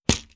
Close Combat Attack Sound 12.wav